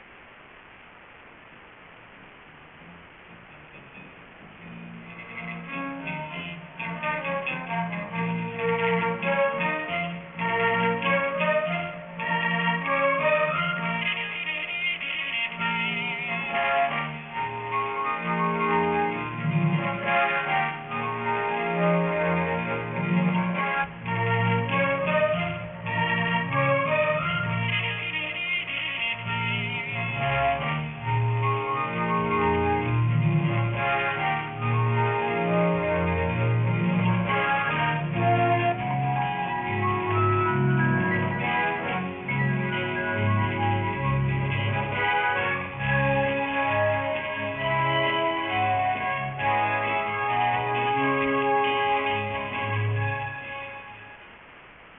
Musica:
Original Track Music